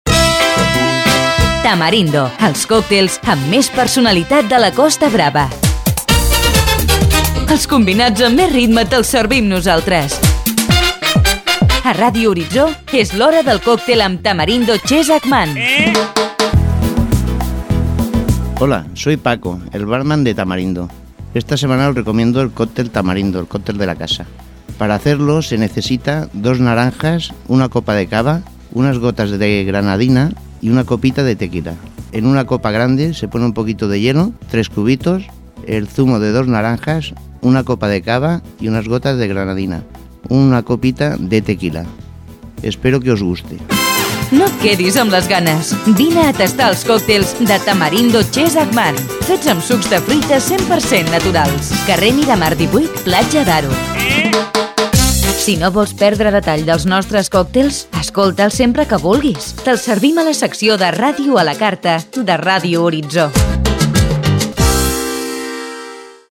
Careta del programa i recepta del coctail Tamarindo